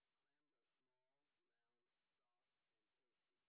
sp21_white_snr20.wav